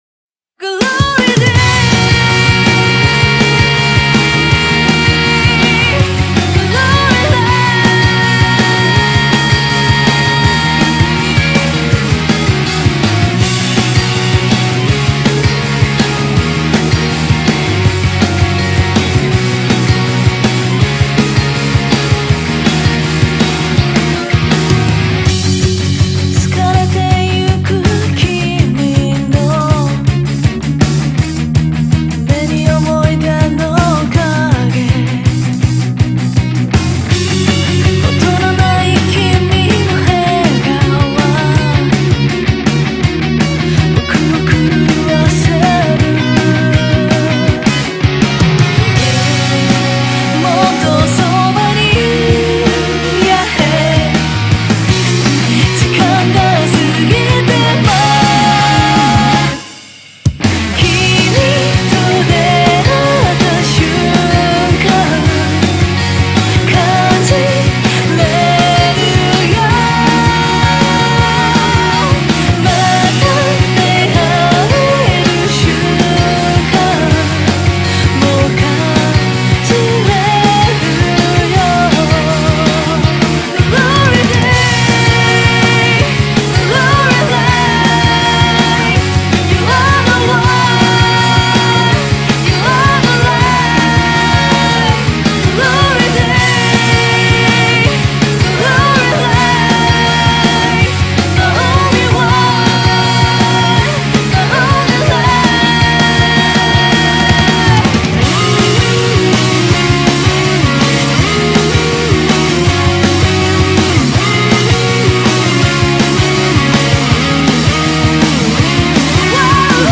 BPM162
opening theme